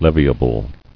[lev·i·a·ble]